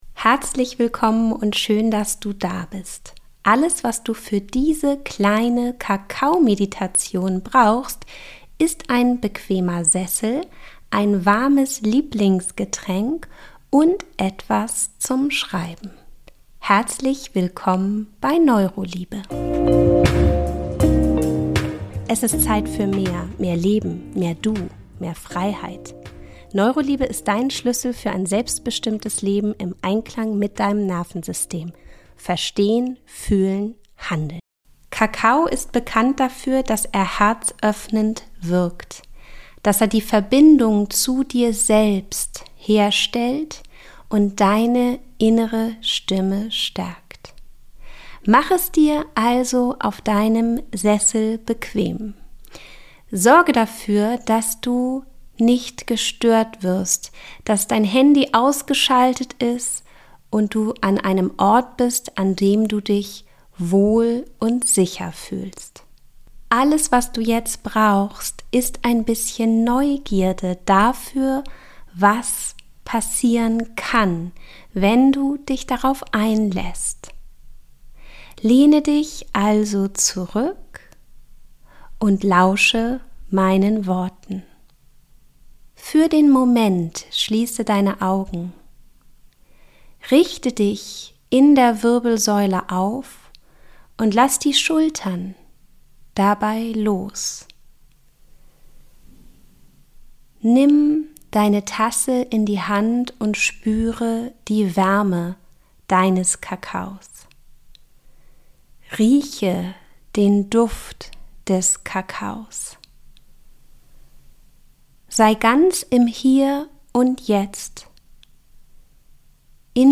(Höre vorher Episode #14, wenn du Kakaomeditationen noch nicht kennst) Ich schenke dir eine geführte Kakaomeditation zum Thema Herzoffenheit – als sanften Abschluss vor der Sommerpause bei Neuroliebe.